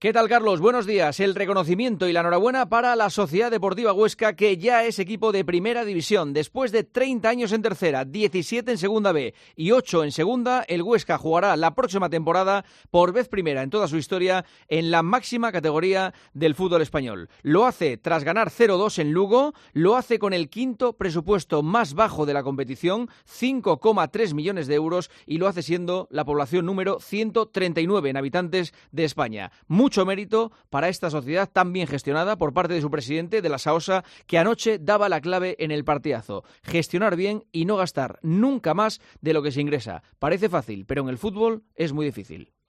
Escucha el comentario del director de 'El Partidazo de COPE', Juanma Castaño, en 'Herrera en COPE'